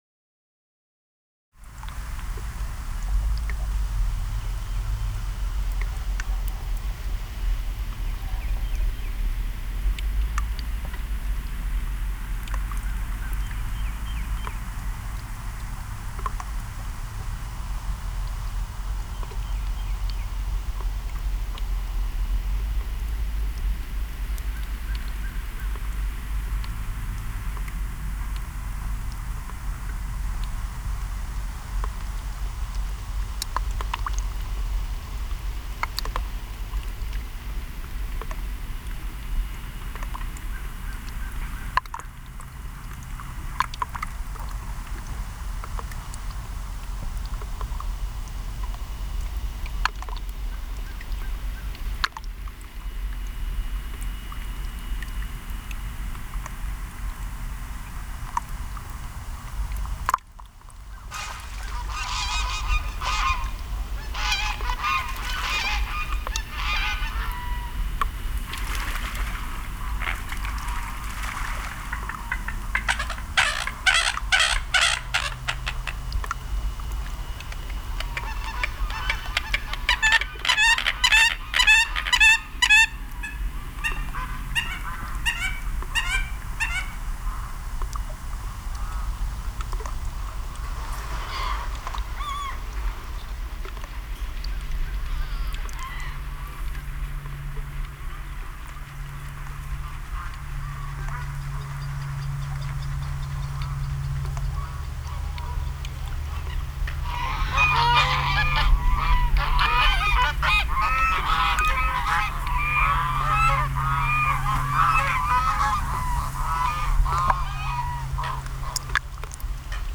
From a Lake to a City is a soundscape that transitions from the gentle splashes of lake water against rocks to the mechanical busyness of a downtown city. Sounds of water splashes, sounds of geese and other birds, and sounds of children’s voices and basketball bounces are eventually overtaken by mechanical sounds of crosswalks, transportation, music from outdoor audio speakers, and finally a firetruck siren, which allows an unsettledness to linger.
For this project, I used a hand held zoom recorder and recorded on location around Lake Palestine near Tyler, Texas, and in downtown Dallas, Texas.